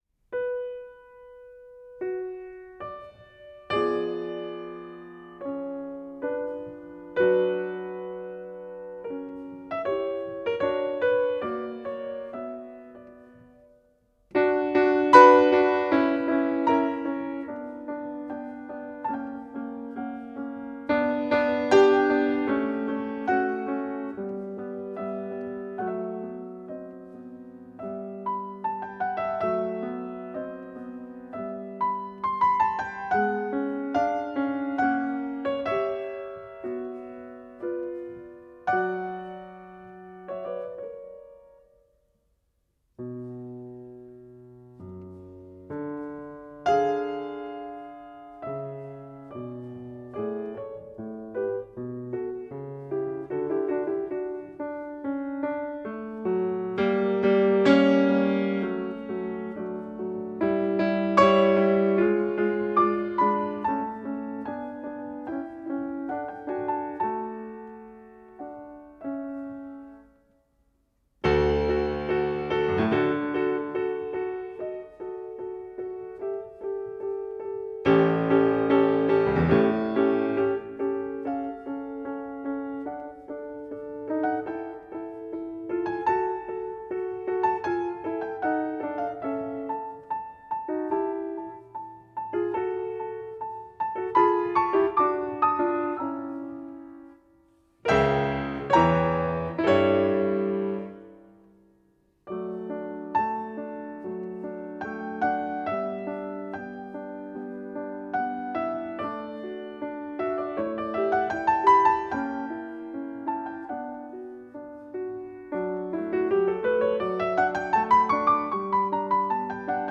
Piano
Adagio in B minor, K.540
Style: Classical